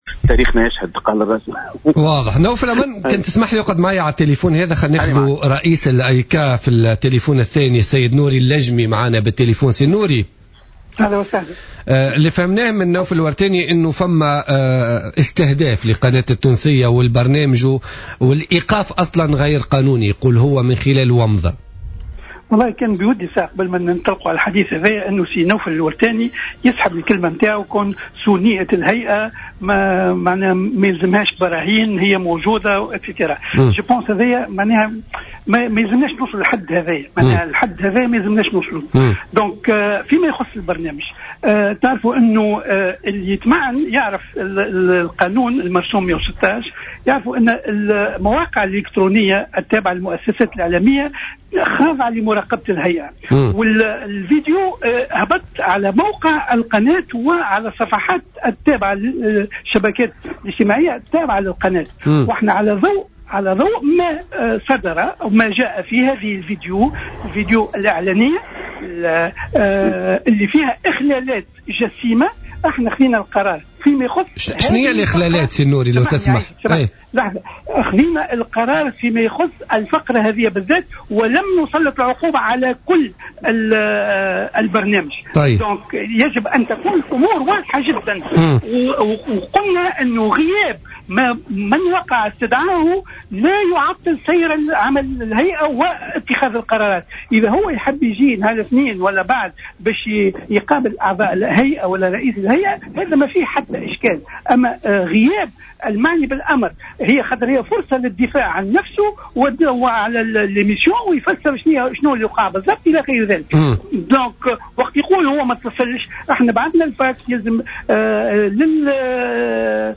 أكد رئيس الهيئة العليا المستقلة للإتصال السمعي البصري النوري اللجمي في مداخلة له في برنامج بوليتيكا اليوم الإثنين 19 جانفي 2014 أن قرار منع بث الحوار جاء على ضوء ماتضمنه شريط الفيديو الموجود على موقع القناة من اخلالات جسيمة مشيرا الى القرار يخص الحوار ولايخص البرنامج .